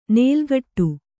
♪ nēlgaṭṭu